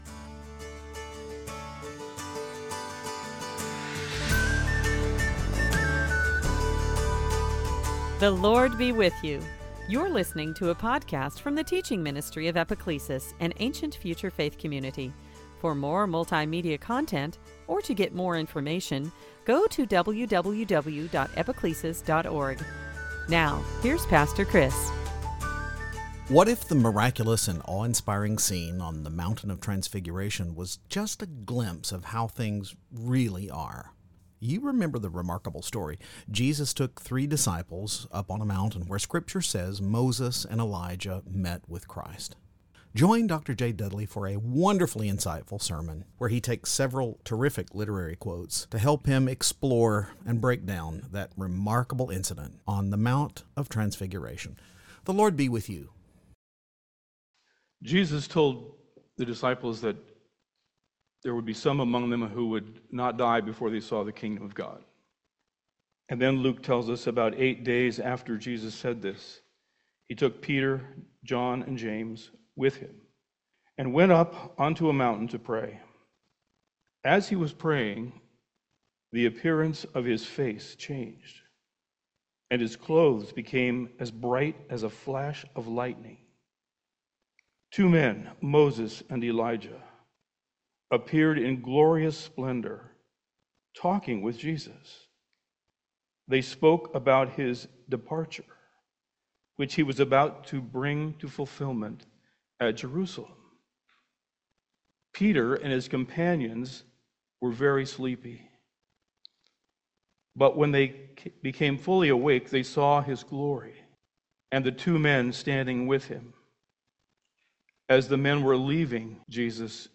Service Type: Transfiguration Sunday